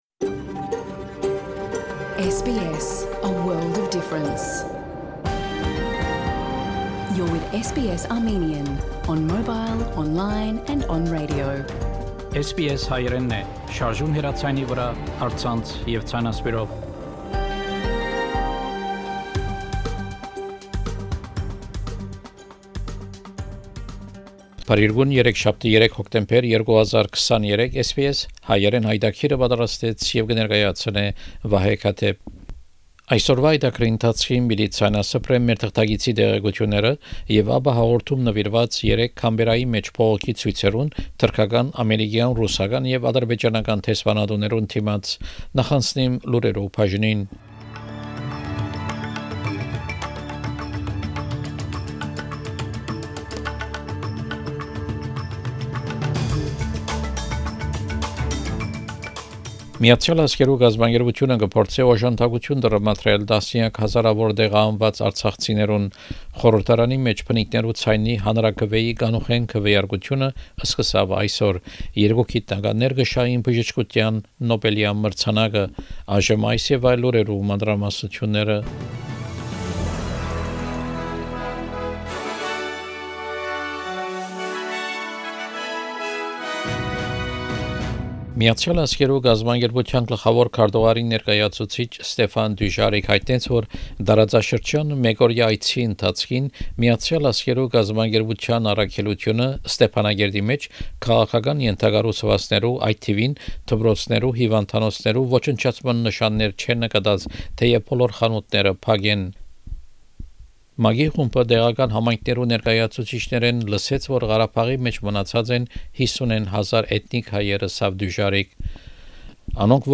SBS Հայերէնի լուրերը քաղուած 3 Հոկտեմբեր 2023 յայտագրէն: